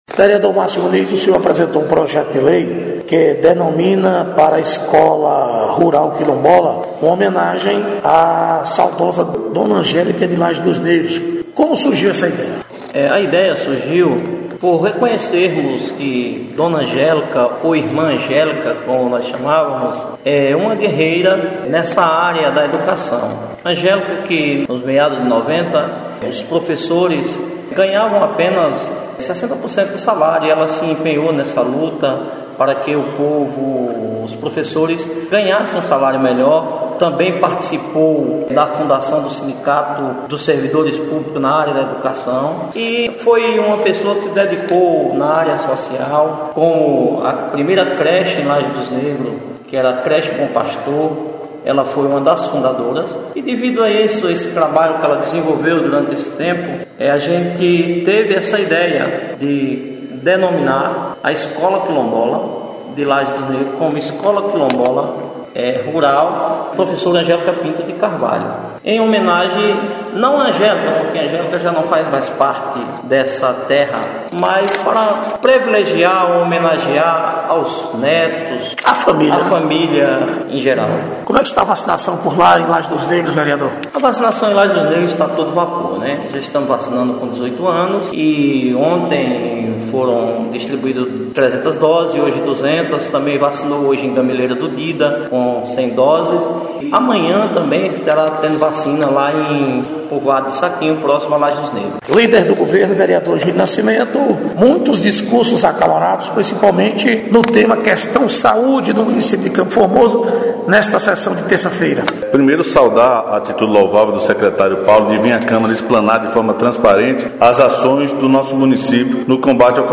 Entrevista: Vereadores